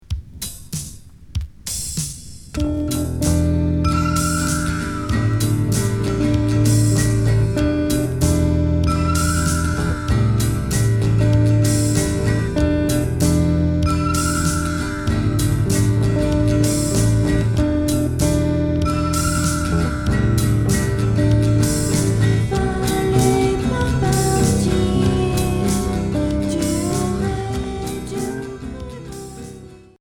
Pop indé Unique 45t